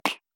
FistHit
cartoon fight fist hit punch slap smack sound effect free sound royalty free Movies & TV